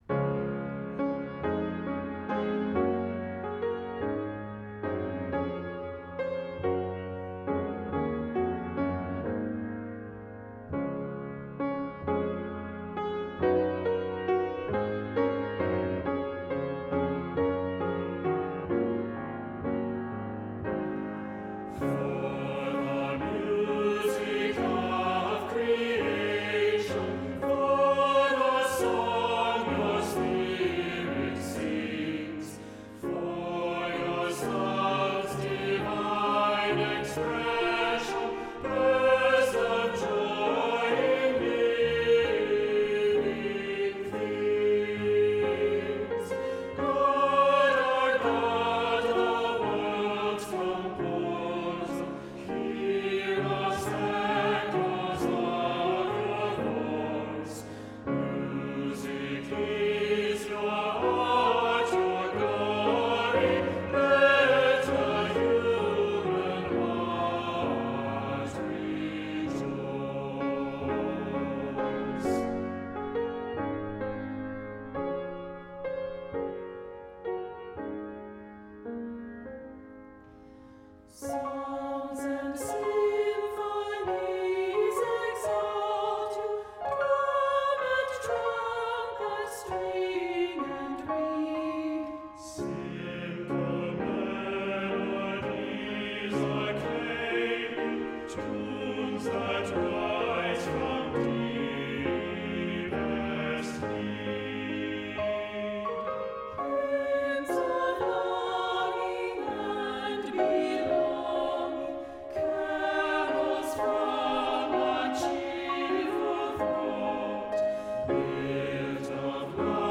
Voicing: "SATB"